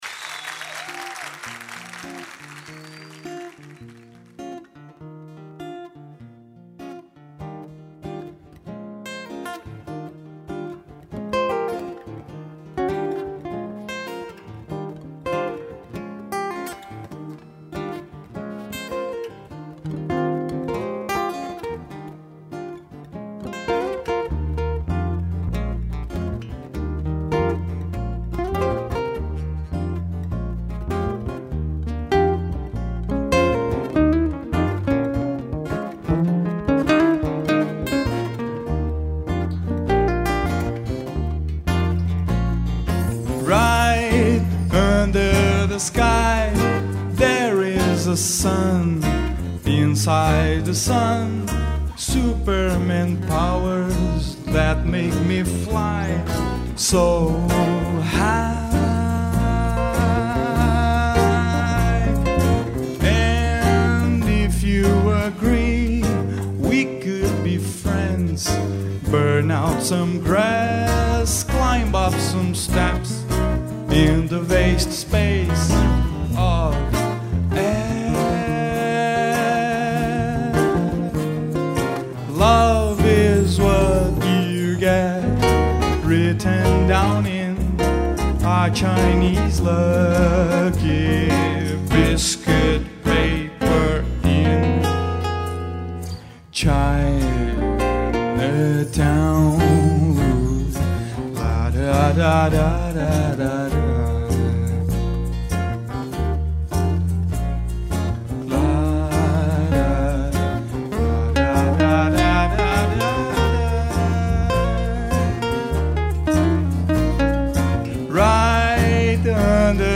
2849   05:34:00   Faixa: 7    Mpb